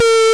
描述：一声蜂鸣，音调略微下降。
Tag: 方波 静音-合成器 中间距 蜂鸣